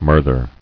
[mur·ther]